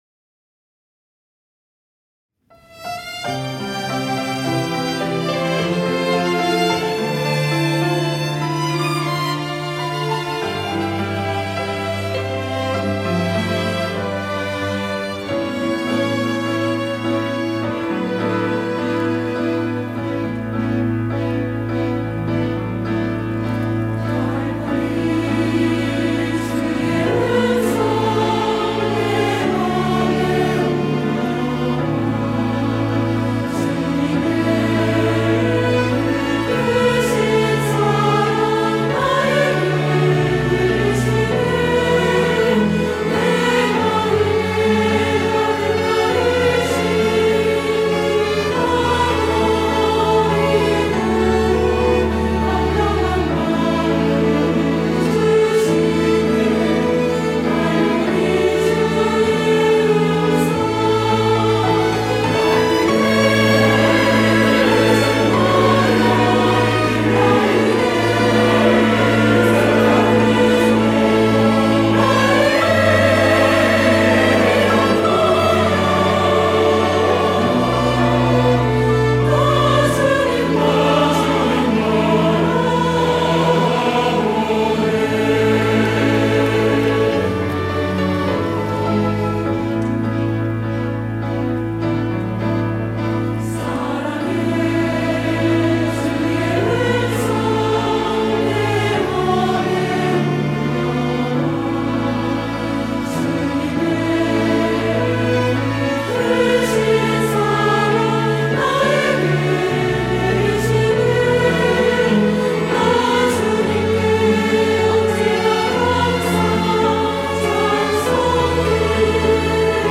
호산나(주일3부) - 보혈의 은혜
찬양대